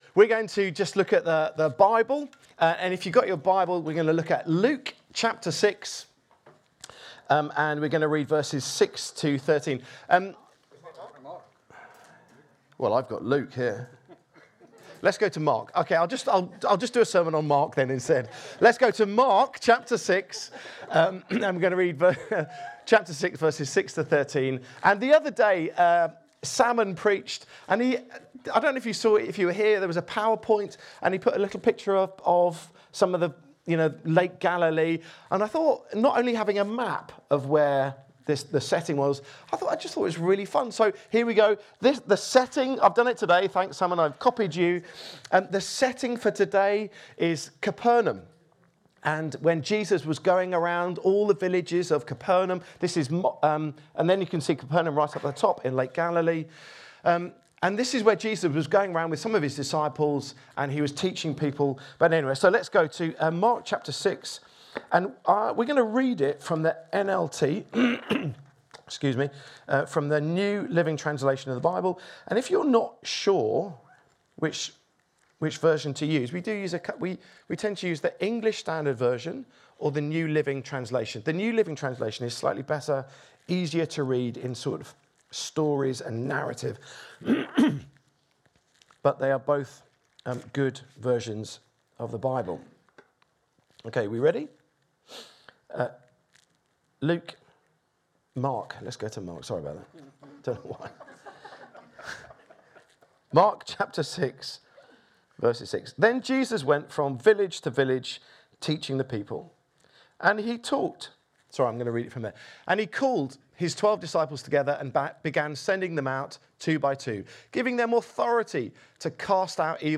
Download Sending out of the twelve | Sermons at Trinity Church